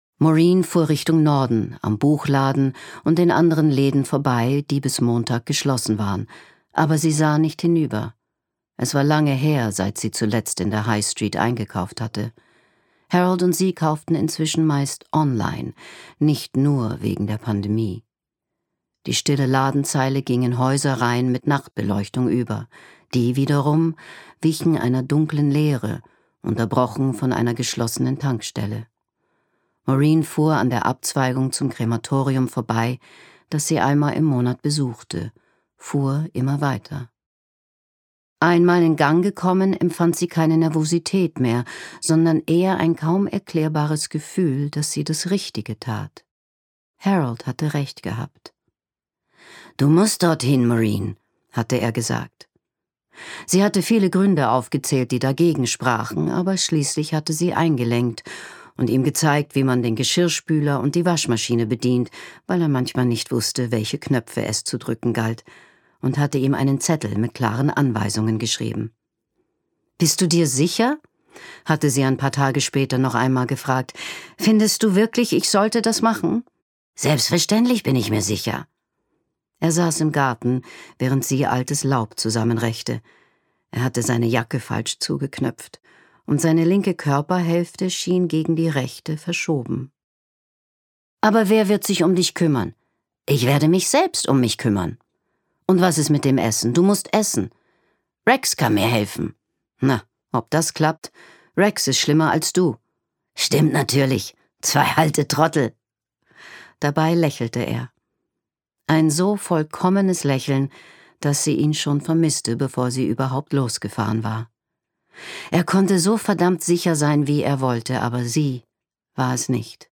Die erstaunliche Entdeckungsreise der Maureen Fry Rachel Joyce (Autor) Leslie Malton (Sprecher) Audio-CD 2023 | 1.